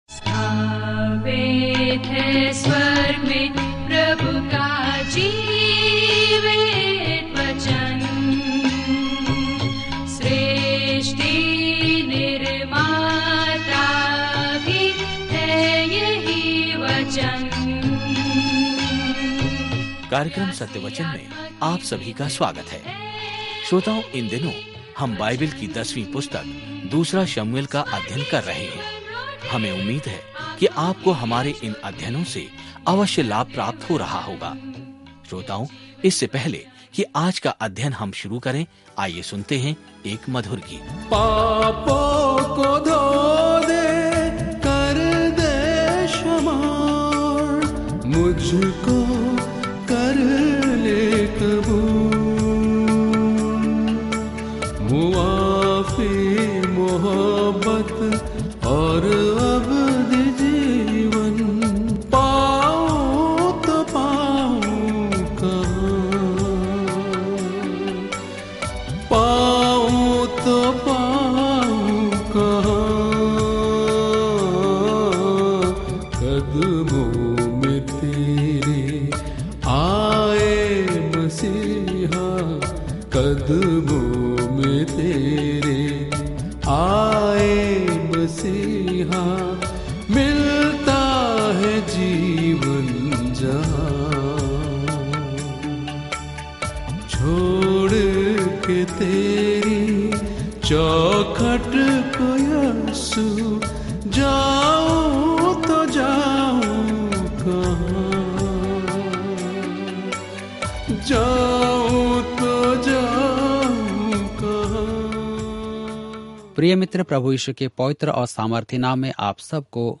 2 सैमुअल के माध्यम से दैनिक यात्रा करें क्योंकि आप ऑडियो अध्ययन सुनते हैं और भगवान के वचन से चुनिंदा छंद पढ़ते हैं।